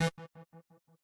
synth1_3.ogg